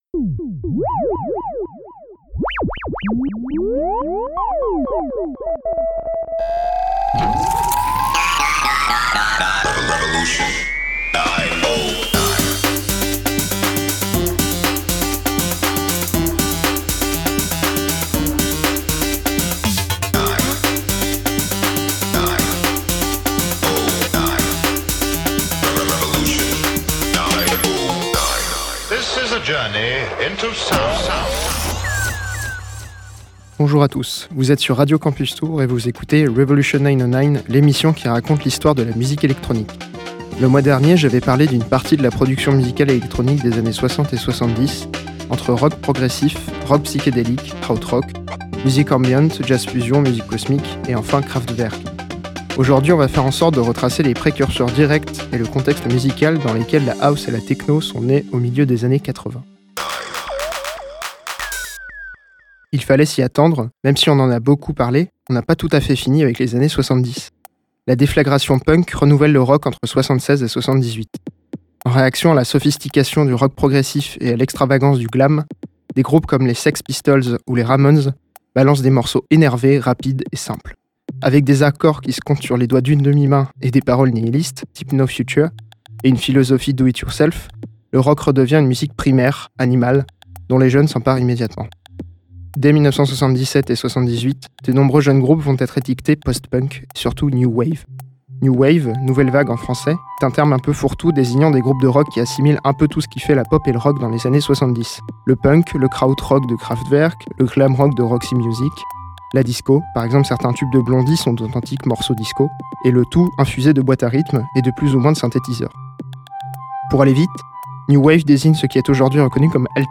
L'histoire de la musique électronique. Au programme ici : le tournant des années 70 et 80, avec la new wave, l'industrial, le dub, le disco, et consorts.
Revolution 909, l’émission qui retrace l’histoire de la musique électronique tous les premiers mardis du mois sur Radio Campus Tours de 20h à 21h.